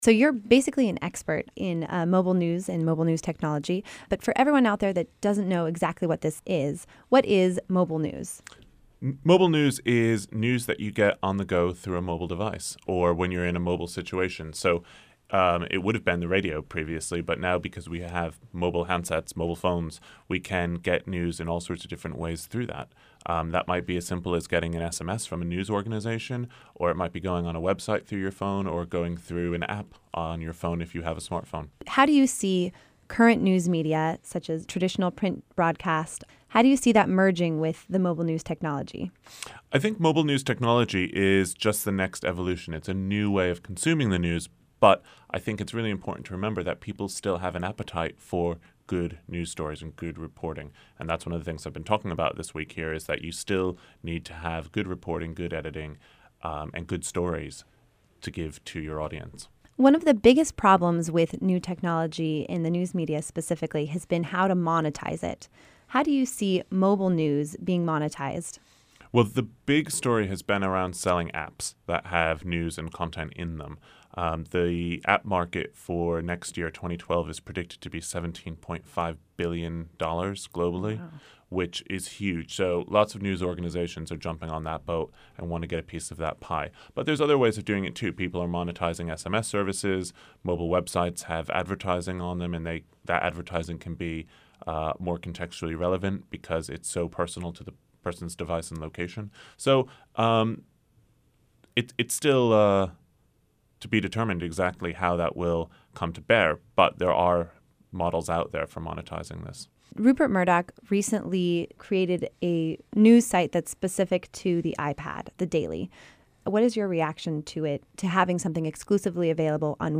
Host interview